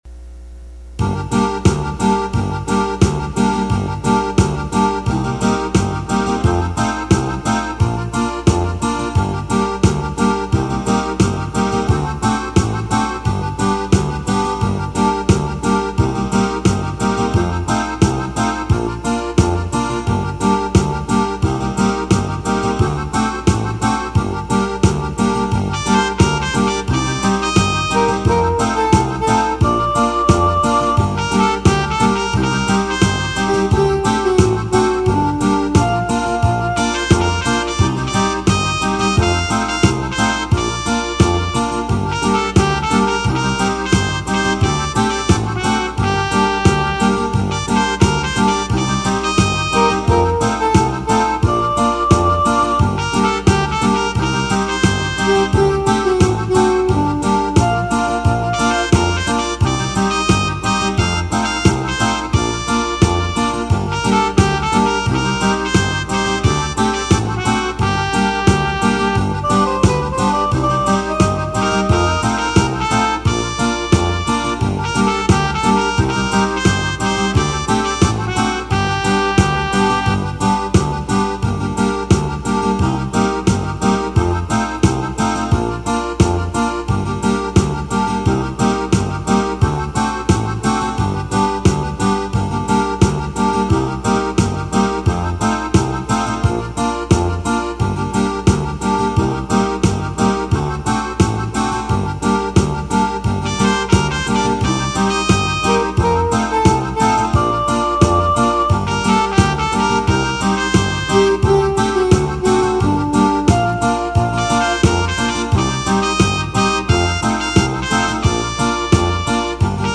Le TRK est le fichier midi en format mp3 sans la mélodie.